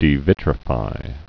(dē-vĭtrə-fī)